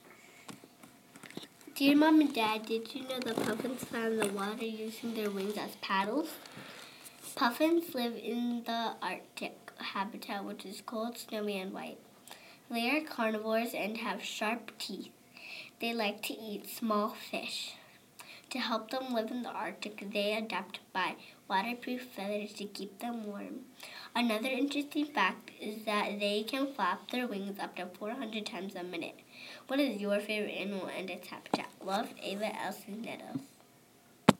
Puffins